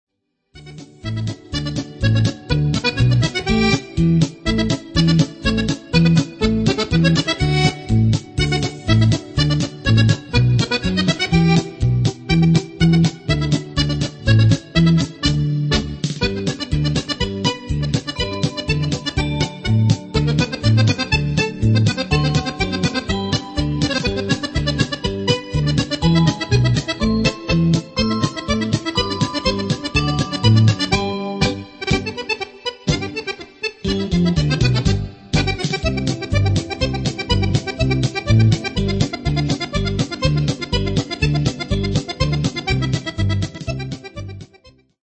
polca